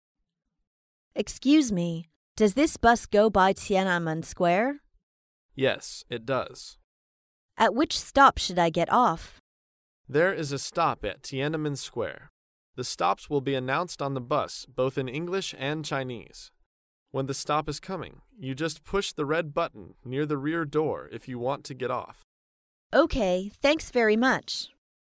在线英语听力室高频英语口语对话 第401期:询问搭乘公共汽车(1)的听力文件下载,《高频英语口语对话》栏目包含了日常生活中经常使用的英语情景对话，是学习英语口语，能够帮助英语爱好者在听英语对话的过程中，积累英语口语习语知识，提高英语听说水平，并通过栏目中的中英文字幕和音频MP3文件，提高英语语感。